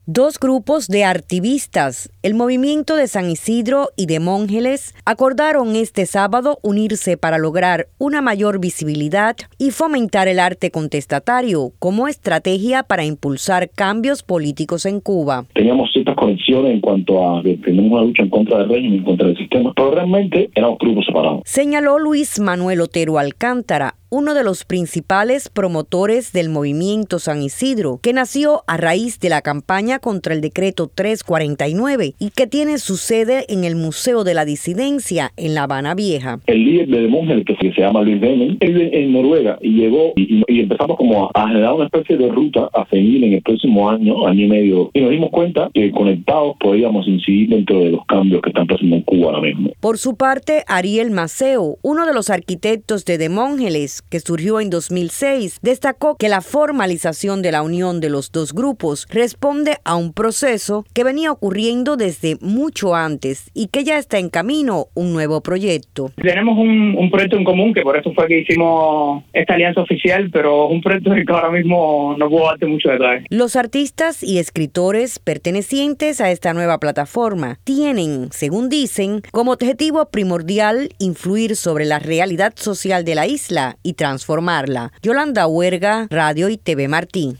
Demongeles y San Isidro. Una entrevista